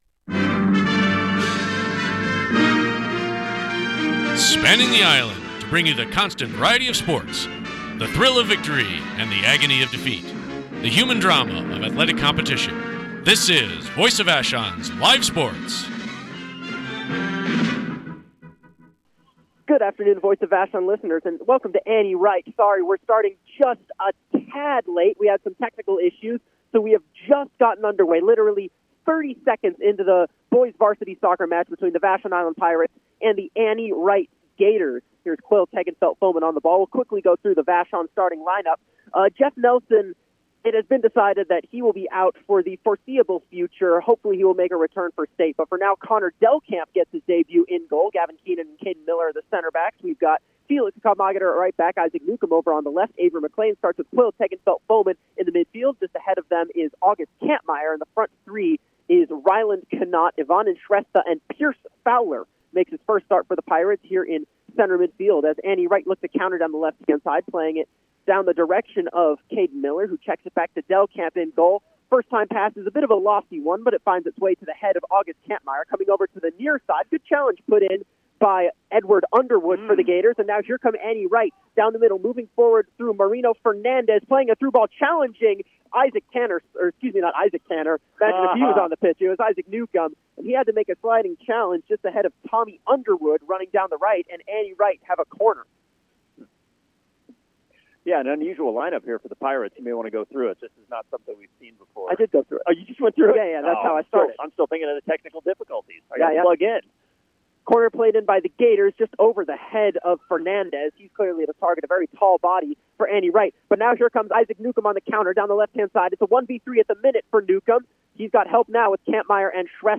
Thank you Vashon Thriftway for helping us bring live sports broadcasts to our island community and beyond.